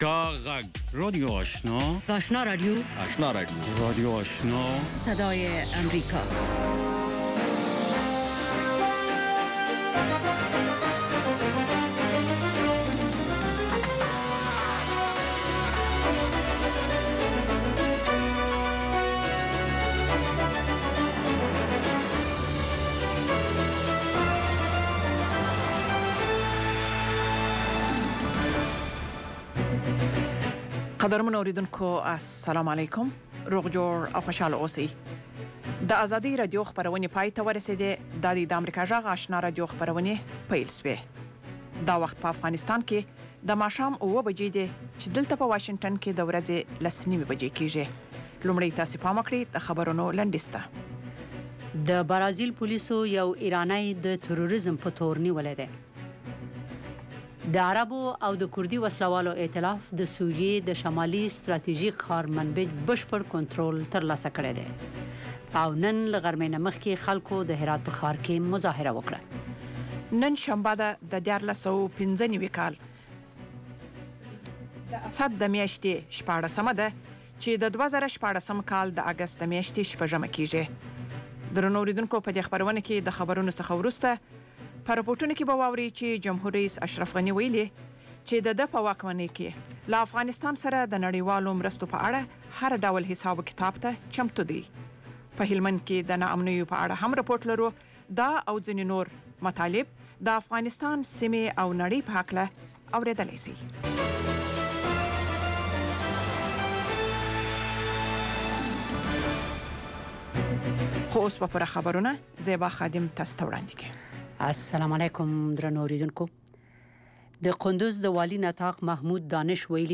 ماښامنۍ خبري خپرونه